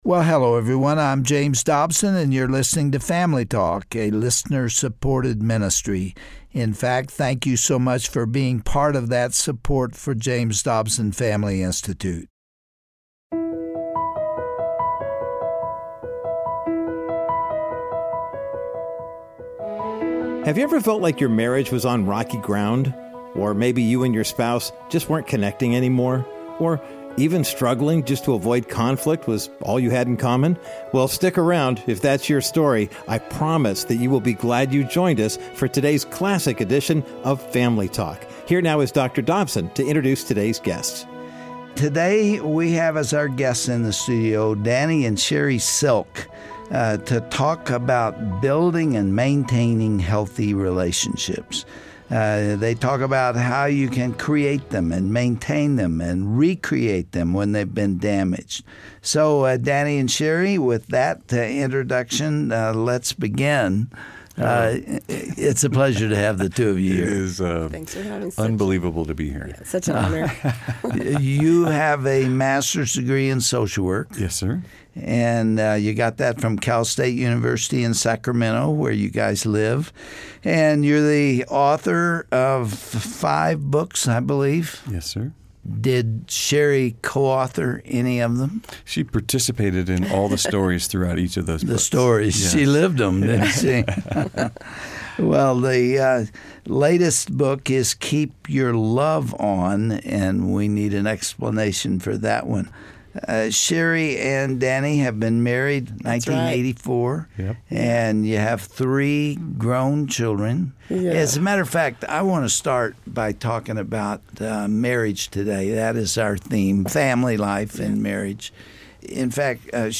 Host Dr. James Dobson